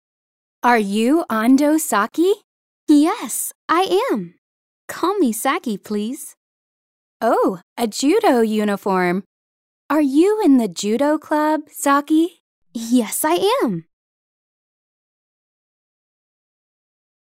2020年5月13日(水) 英語科　教科書予習用　リスニング教材について